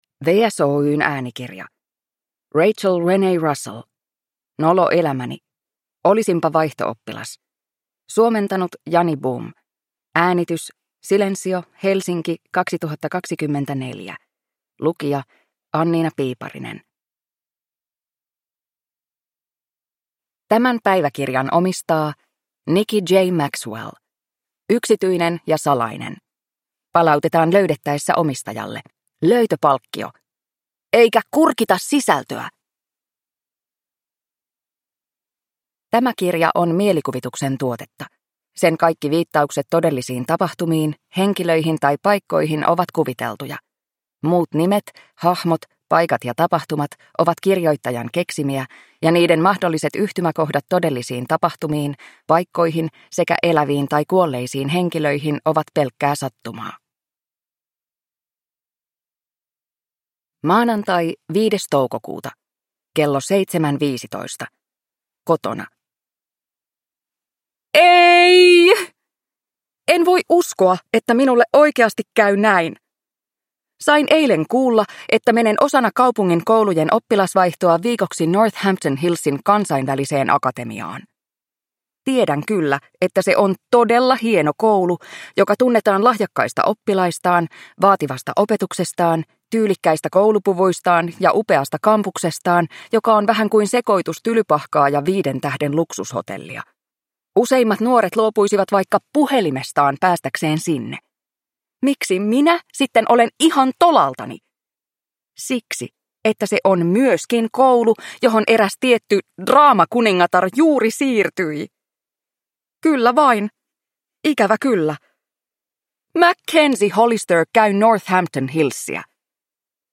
Nolo elämäni: Olisinpa vaihto-oppilas – Ljudbok